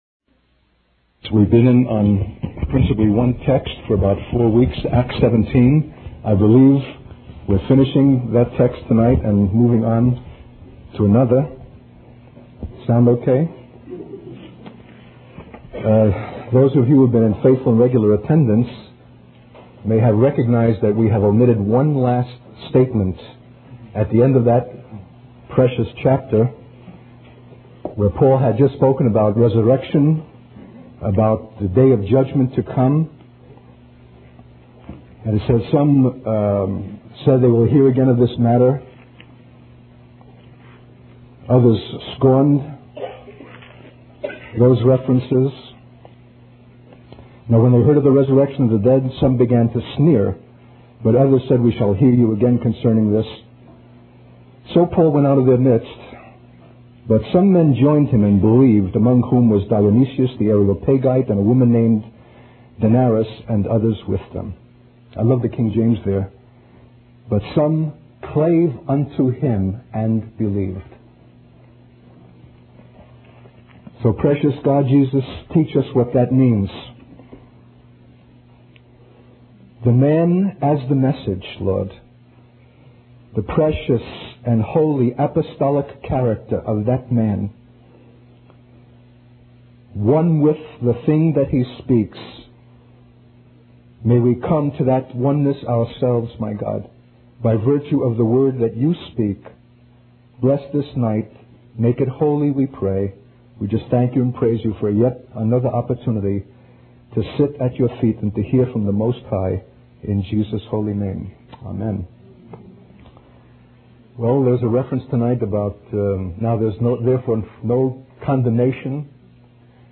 In this sermon, the speaker reflects on his observations of the ever-changing trends and fads in fashion over the course of 57 years. He emphasizes the importance of not being impressed or consumed by these worldly things, but rather focusing on the call and ministry given by God.